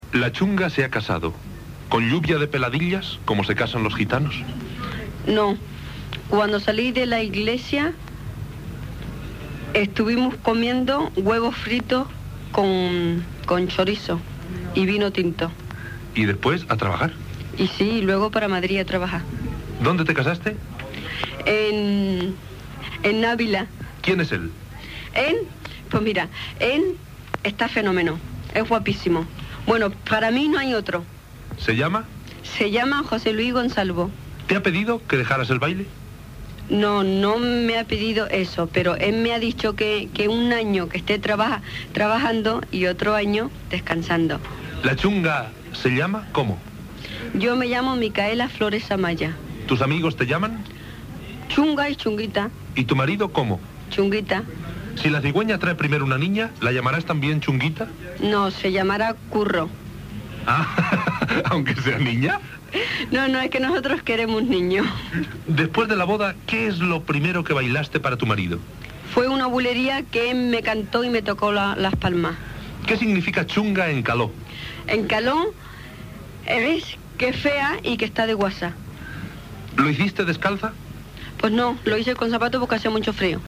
La balladora gitana La Chunga (Micaela Flores Amaya) parla de com va ser el seu casament